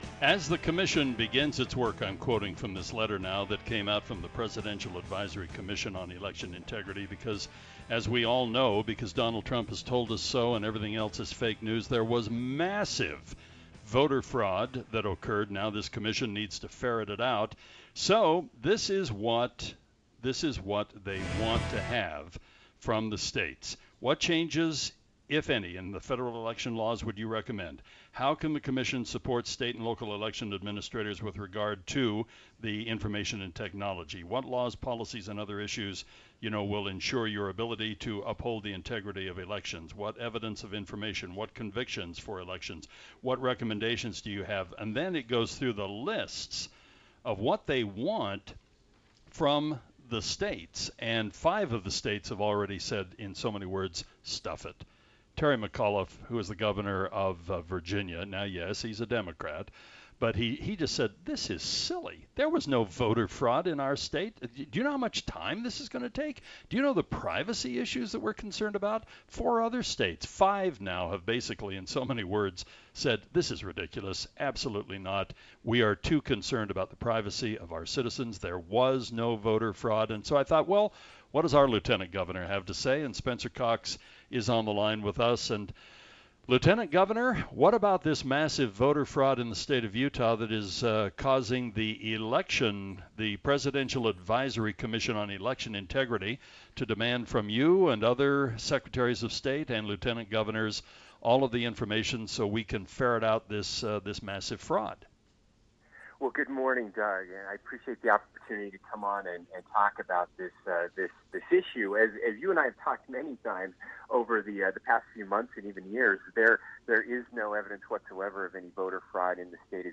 Interview: Utah Lt Gov Spencer Cox on voter records, election integrity